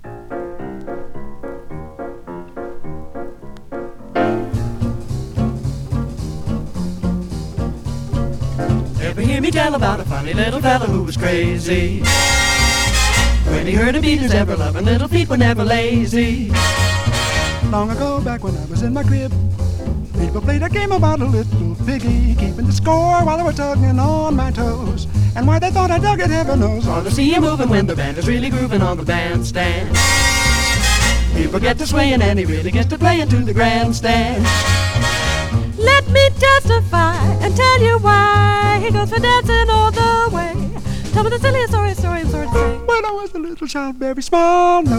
ヒップに、クールに、絶妙なヴォーカルアンサンブル、軽やかに小粋にビッグ・バンド・スウィング・ジャズが楽しい良盤。